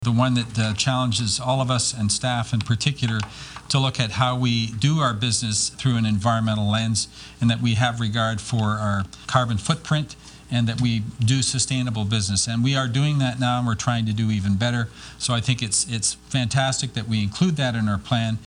Councillor Terry Cassidy called it “the best strategic plan the city has ever had” and was especially pleased with the section on environmental stewardship